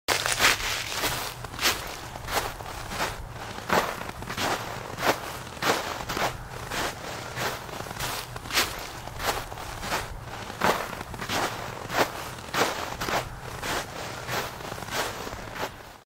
دانلود صدای راه رفتن کفش روی برف 2 از ساعد نیوز با لینک مستقیم و کیفیت بالا
جلوه های صوتی